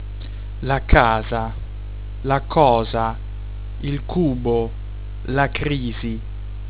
8.) "c" vor "a"/"o"/"u" od." Konsonant" (casa, cosa, cubo, crisi)
[k]